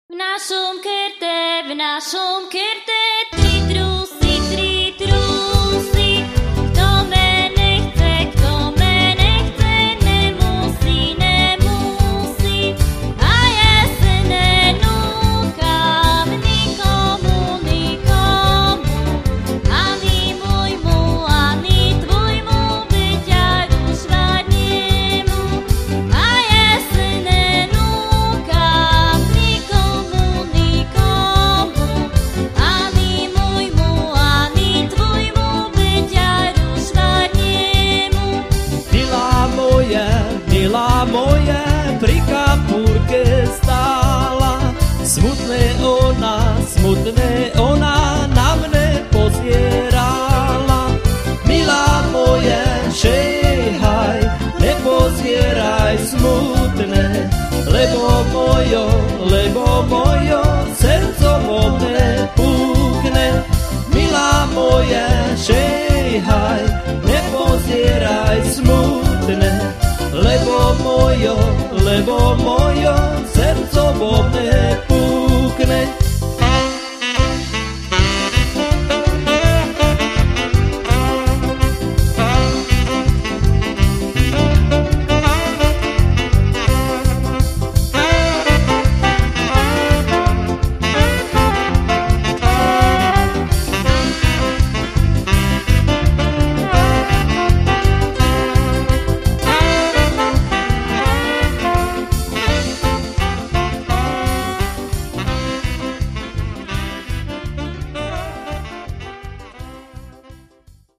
Nahrali ho vtedajší členovia kapely.
ľudové pesničky z Vlachova a jeho okolia.